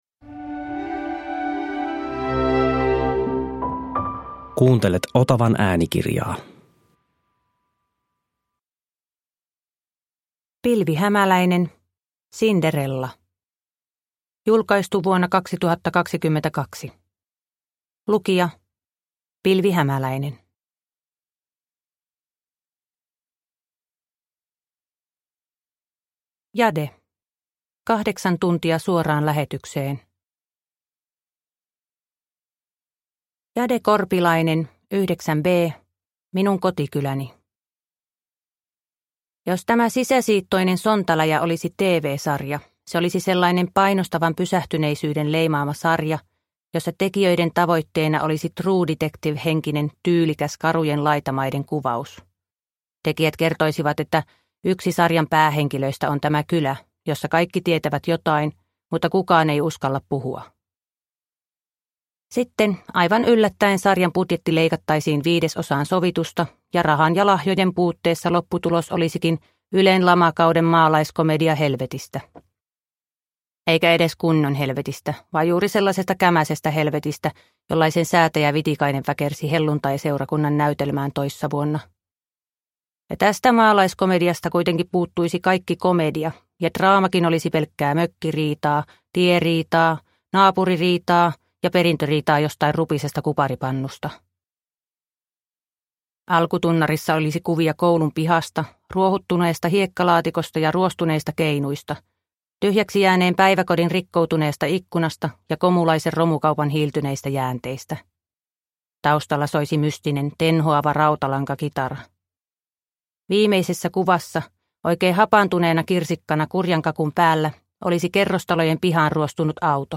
Cinderella – Ljudbok